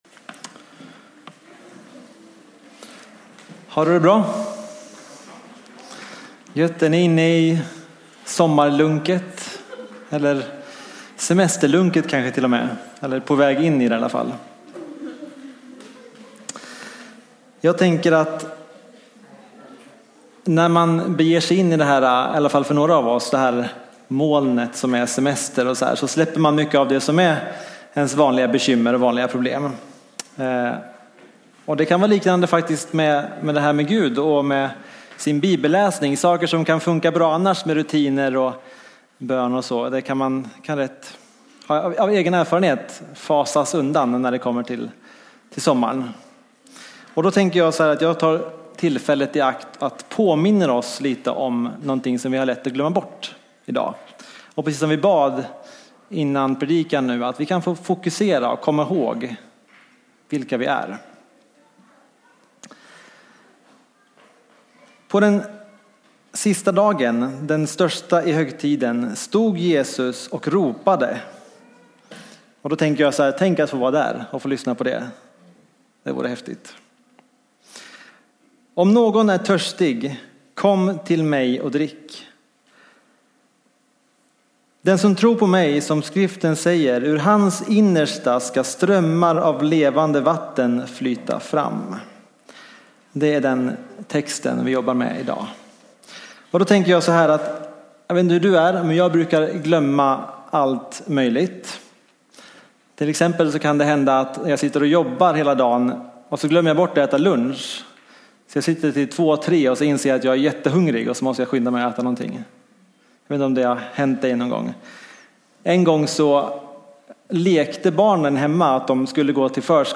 A predikan from the tema "Sommargudstjänster."
Predikningar från sommargudstjänsterna i Skogsrokyrkan 2017.